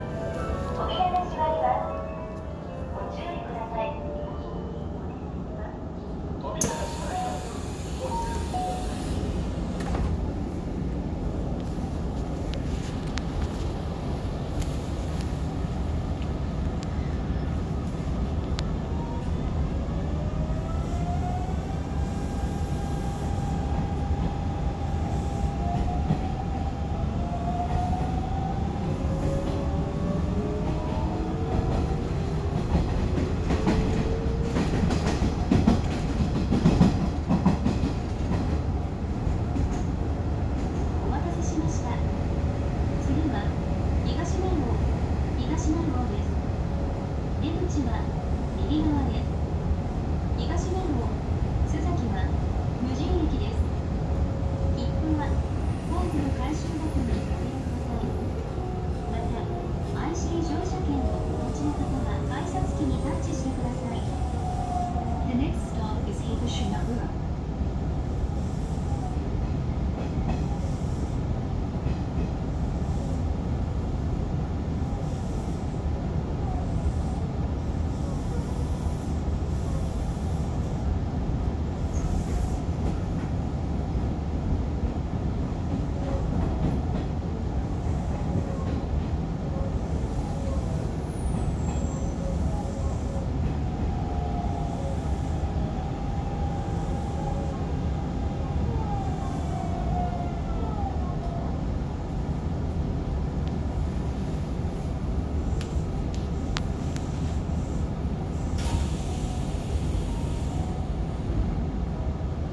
・5500系走行音
【阪神本線】尼崎センタープール前→武庫川（1分57秒：640KB）
三菱GTO。